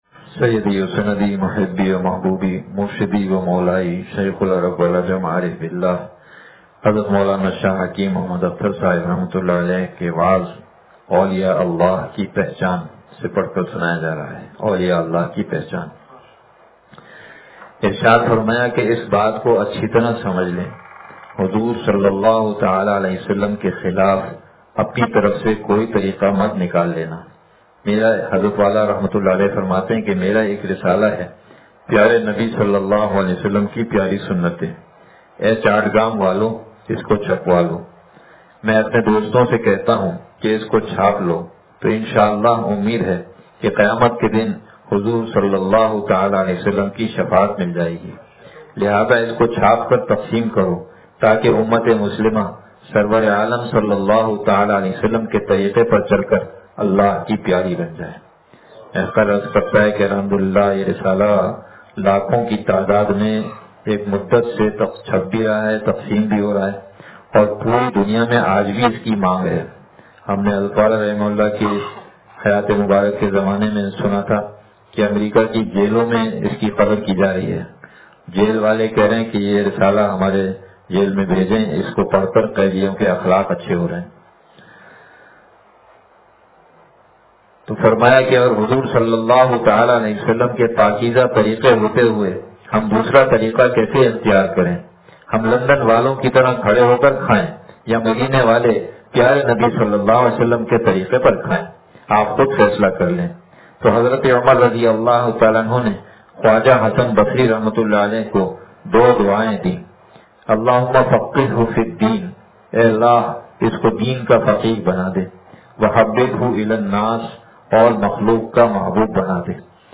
وعظ اولیا اللہ کی پہچان سے – دنیا کی حقیقت – نشر الطیب فی ذکر النبی الحبیب صلی اللہ علیہ وسلم